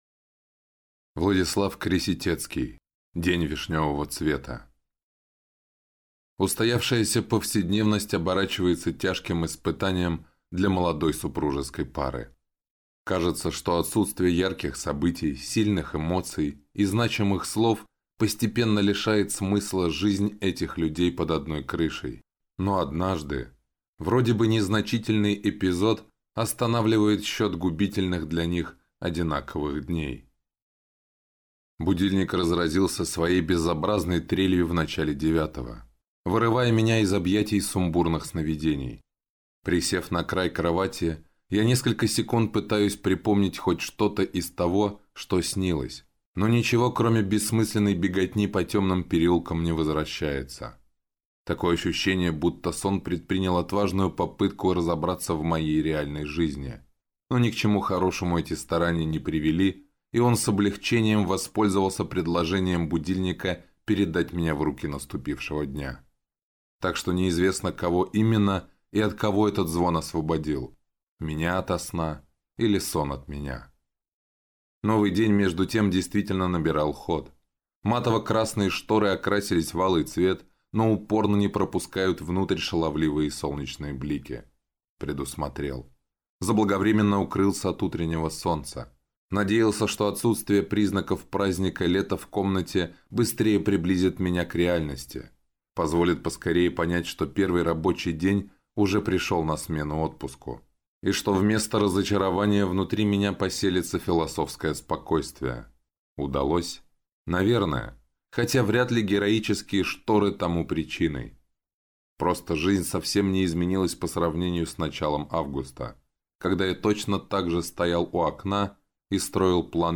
Аудиокнига День вишневого цвета | Библиотека аудиокниг